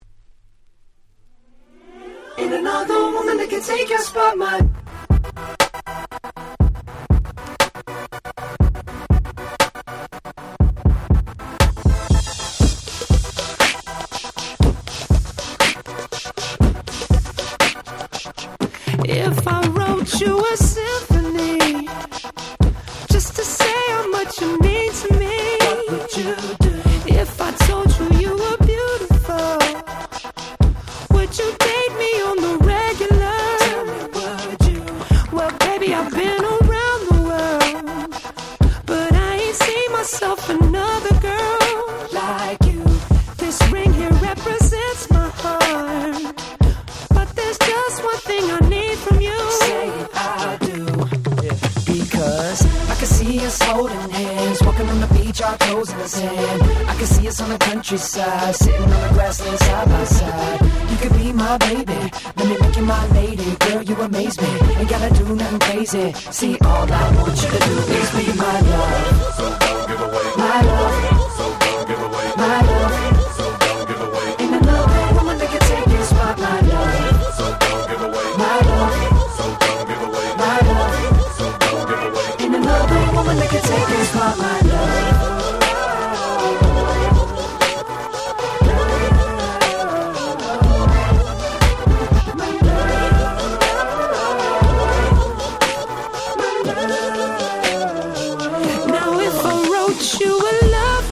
06' Super Hit R&B !!